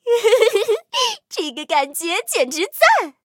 M10狼獾补给语音.OGG